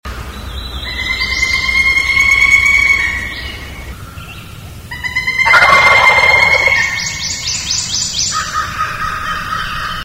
forest-birds_44.mp3